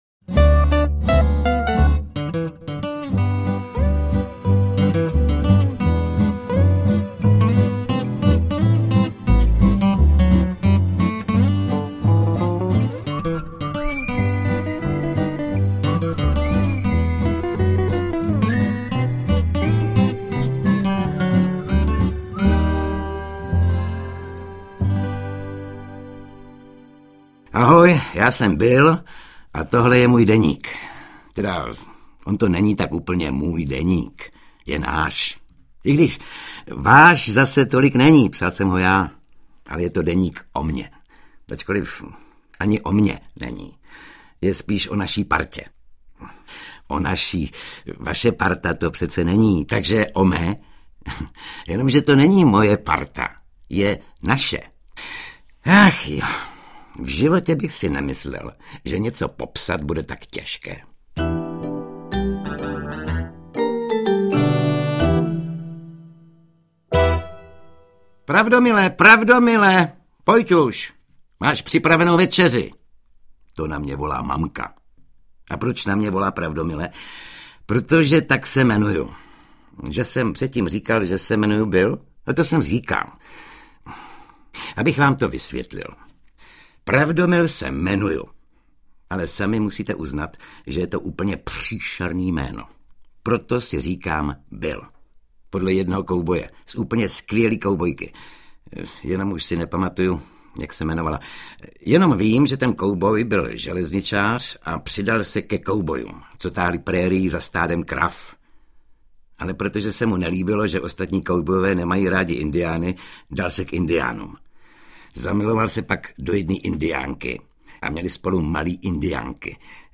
Interpret:  Oldřich Kaiser
AudioKniha ke stažení, 10 x mp3, délka 1 hod. 38 min., velikost 89,7 MB, česky